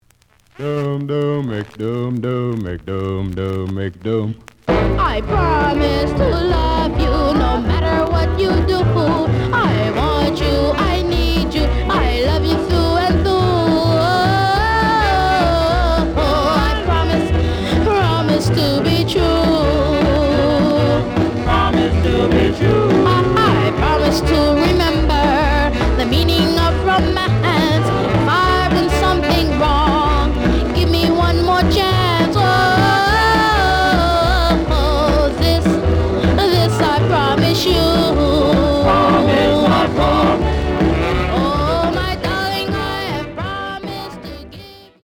The audio sample is recorded from the actual item.
●Genre: Rhythm And Blues / Rock 'n' Roll
Slight noise on beginning of B side, but almost good.)